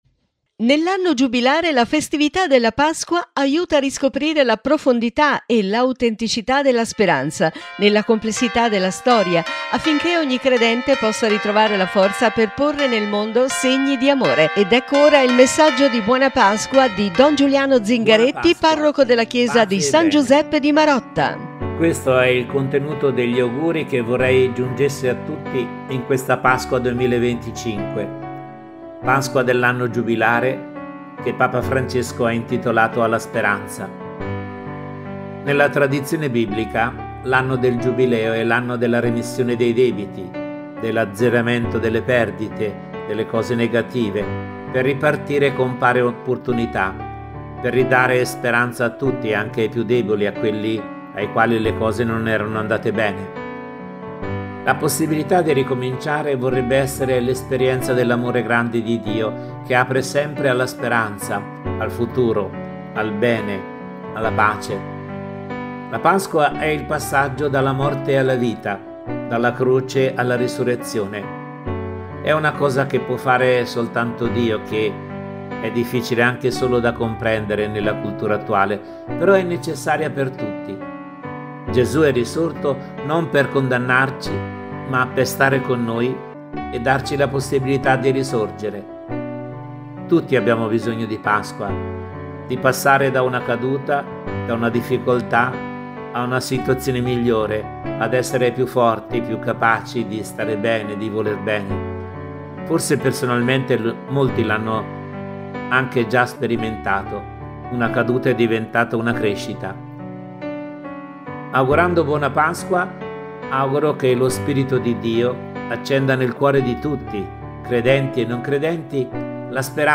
Domenica 20 Aprile Pasqua di Resurrezione – Messaggio audio